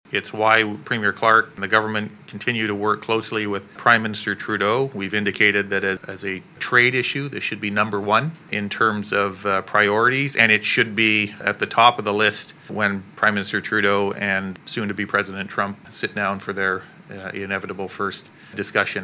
BC’s finance minister Mike De Jong says he’s worried about the softwood lumber dispute….